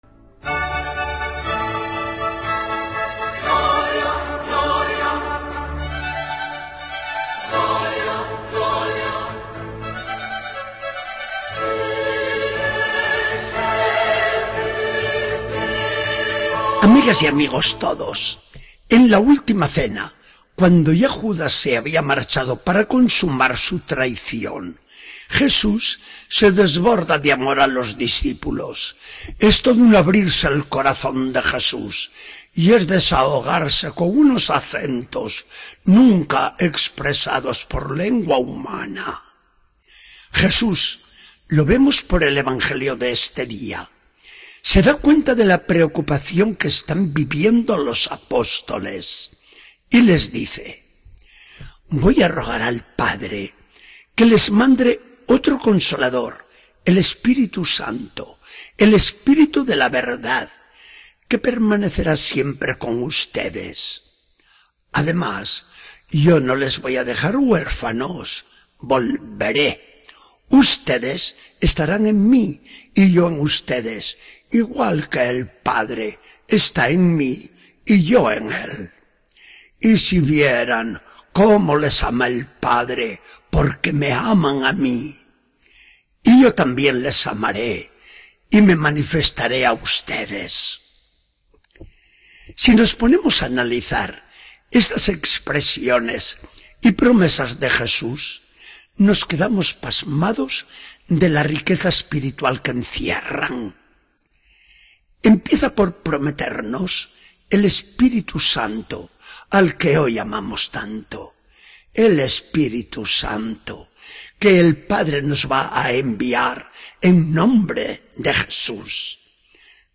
Charla del día 25 de mayo de 2014. Del Evangelio según San Juan 14, 15-21.